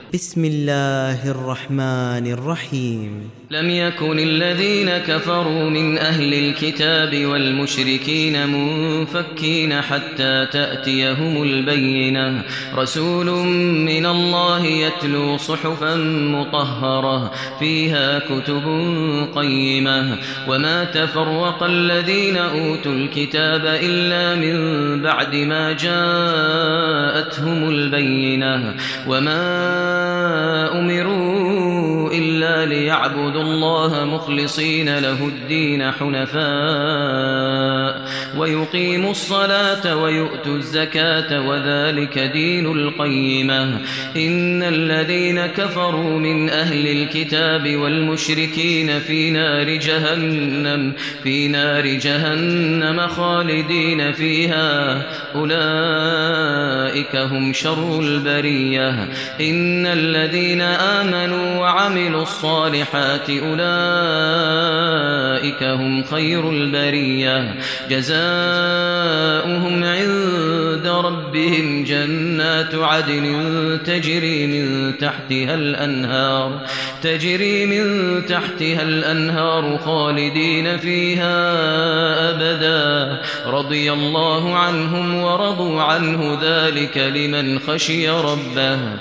Surat Al Bayyina Download mp3 Maher Al Muaiqly Riwayat Hafs dari Asim, Download Quran dan mendengarkan mp3 tautan langsung penuh